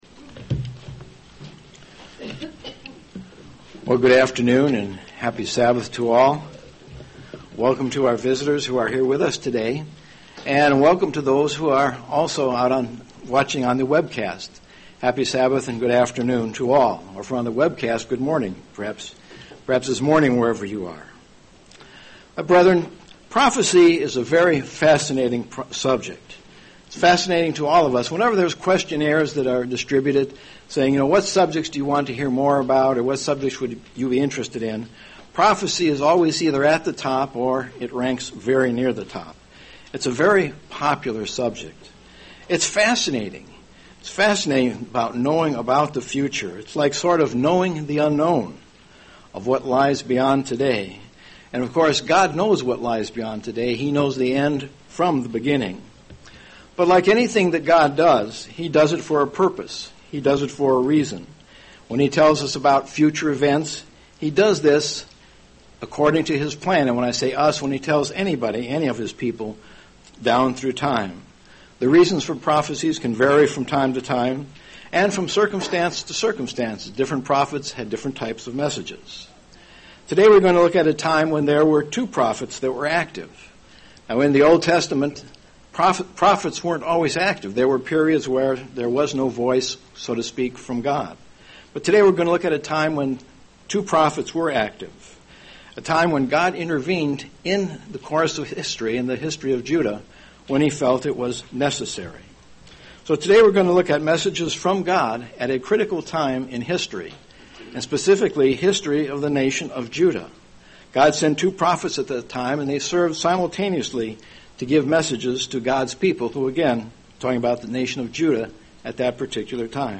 No summary available UCG Sermon Transcript This transcript was generated by AI and may contain errors.